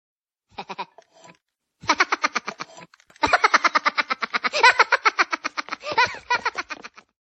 Kategorien Lustige